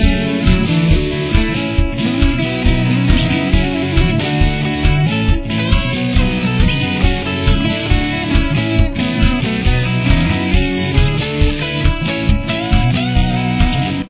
Lead Guitar: